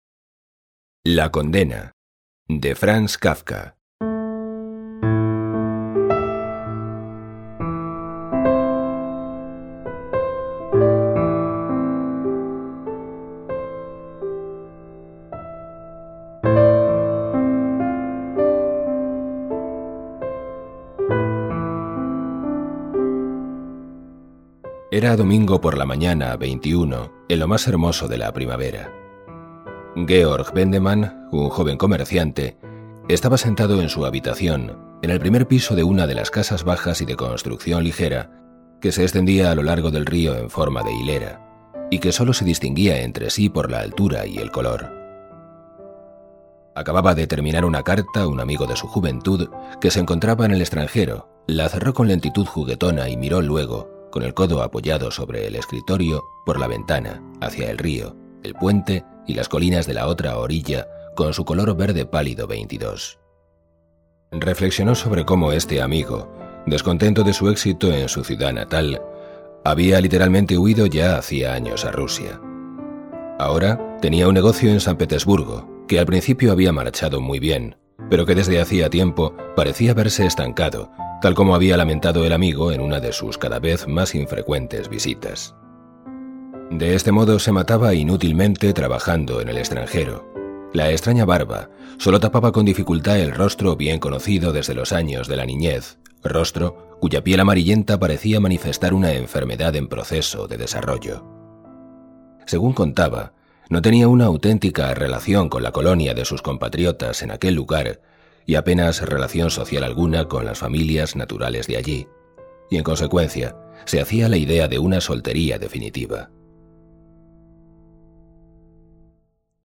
Música: Frozen Silence (cc:by-sa)